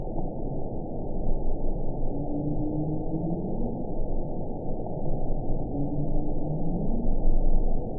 event 910078 date 01/13/22 time 14:25:04 GMT (3 years, 3 months ago) score 7.20 location TSS-AB06 detected by nrw target species NRW annotations +NRW Spectrogram: Frequency (kHz) vs. Time (s) audio not available .wav